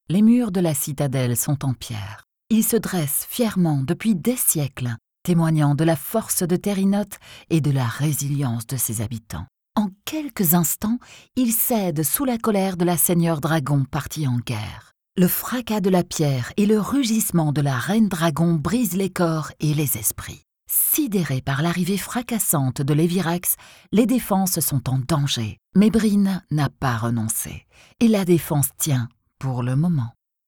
French voice over
Warm, Deep, Reliable, Mature, Corporate
Corporate